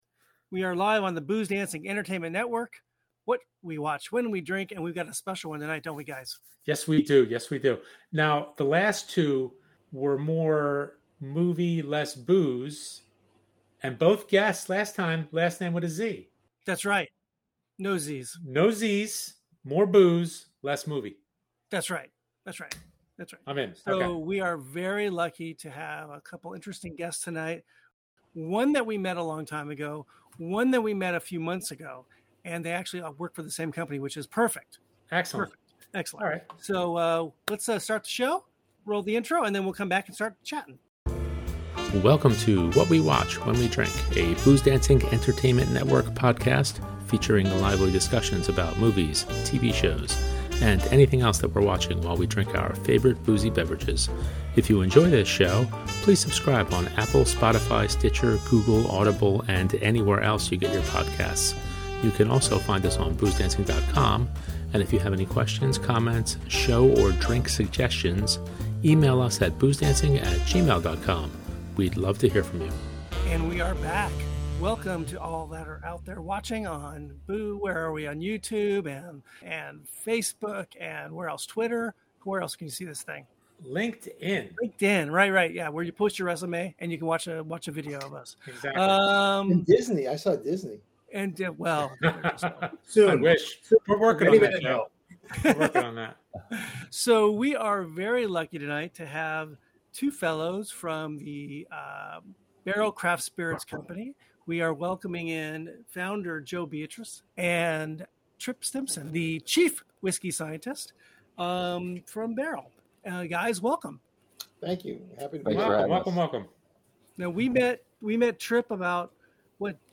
We went LIVE again!